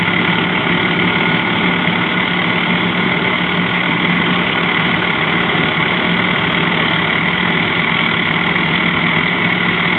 Двигатель трактора гудит:
tractor2.wav